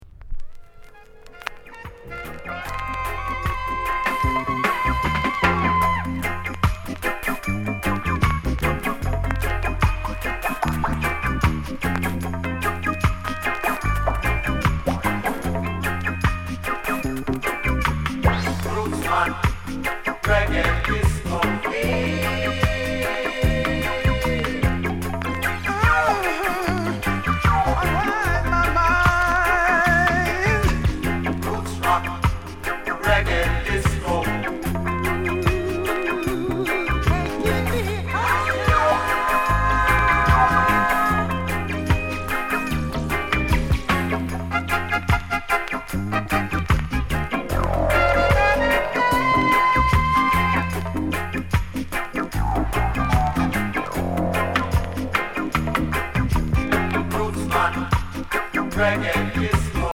KILLER ROOTS INST